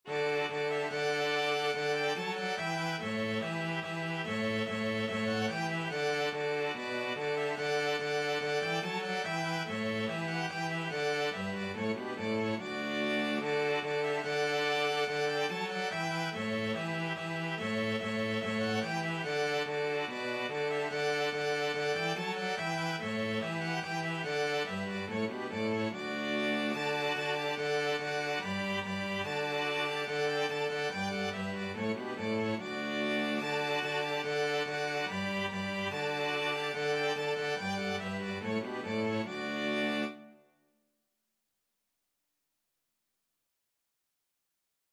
Free Sheet music for String Quartet
Violin 1Violin 2ViolaCello
D major (Sounding Pitch) (View more D major Music for String Quartet )
4/4 (View more 4/4 Music)
Classical (View more Classical String Quartet Music)
danserye_18_ronde_STRQ.mp3